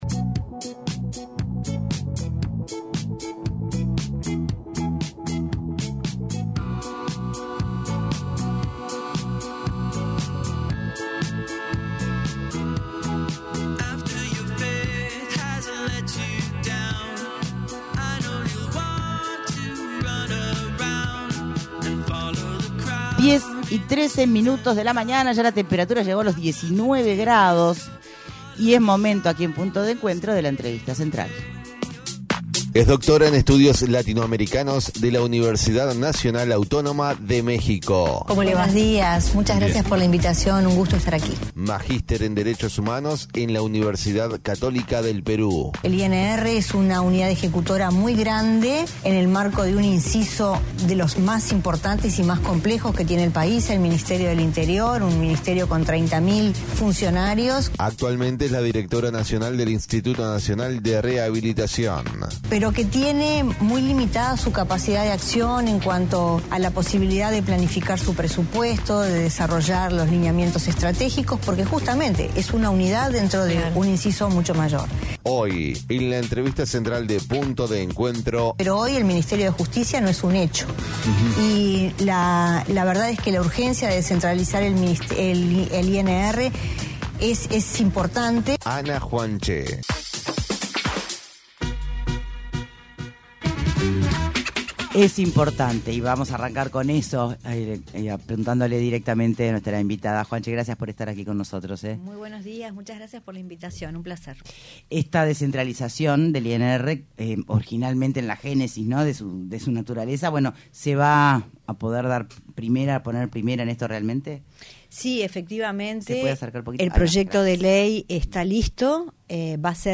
ENTREVISTA: ANA JUANCHE ¿El Modelo Bukele es para analizar aplicarlo en Uruguay?